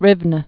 (rĭvnə, rēwnĕ) or Rov·no (rôvnə)